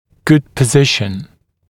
[gud pə’zɪʃ(ə)n][гуд пэ’зиш(э)н]правильное положение, хорошее положение